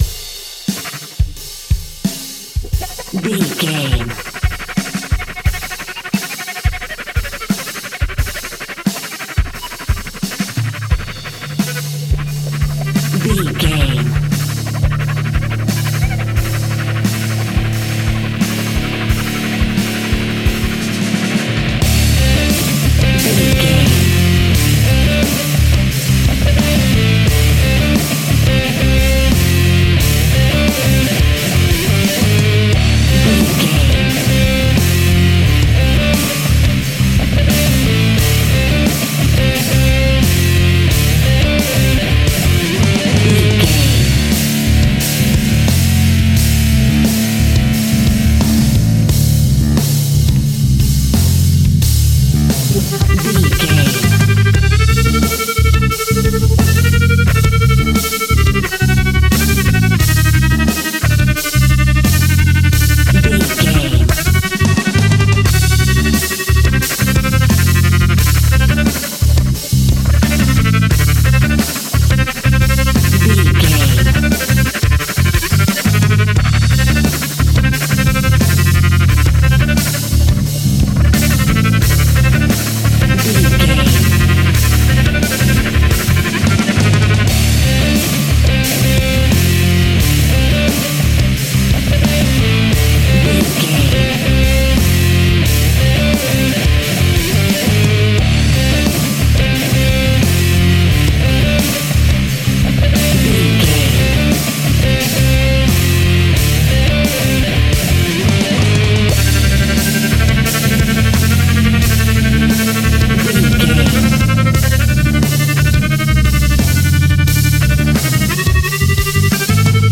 Epic / Action
Fast paced
Aeolian/Minor
hard rock
heavy metal
distortion
instrumentals
Rock Bass
heavy drums
distorted guitars
hammond organ